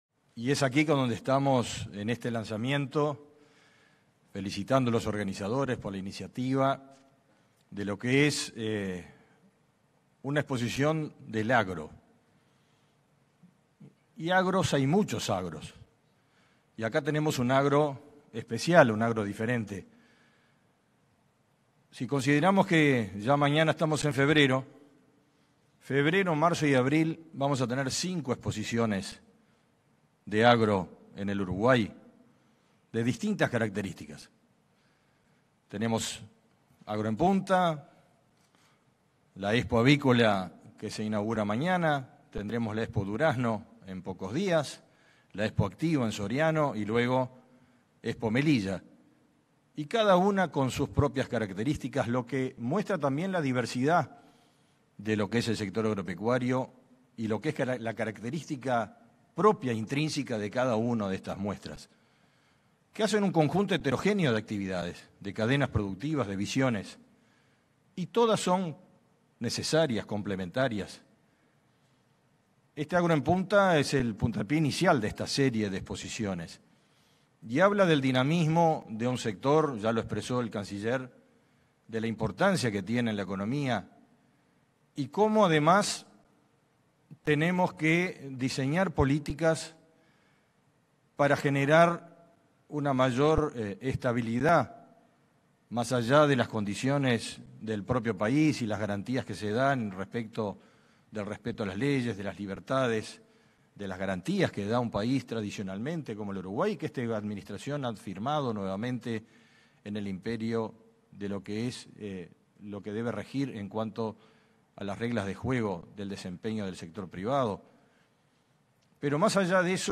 Palabras del ministro de Ganadería, Fernando Mattos
Palabras del ministro de Ganadería, Fernando Mattos 31/01/2024 Compartir Facebook X Copiar enlace WhatsApp LinkedIn En el marco de la feria agroexportadora denominada Agro en Punta Expo & Business, este 31 de enero, se expresó el ministro de Ganadería, Fernando Mattos.